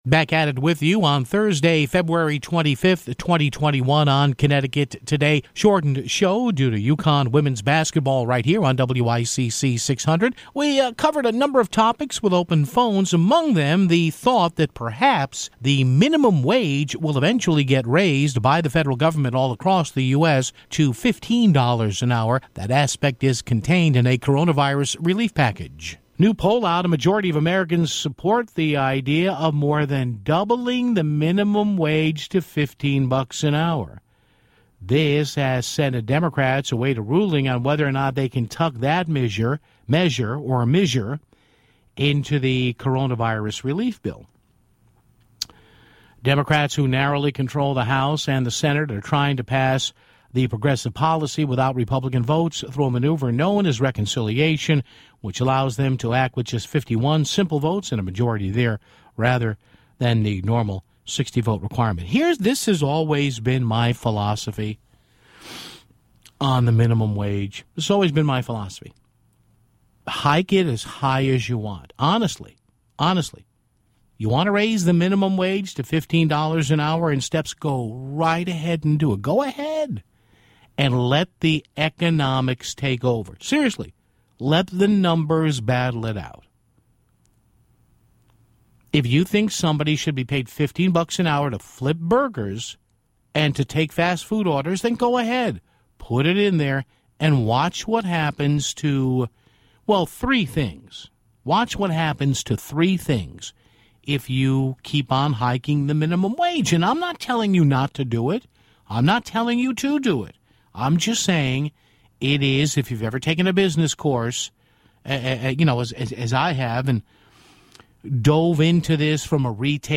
He took some calls and he spoke about it at length himself.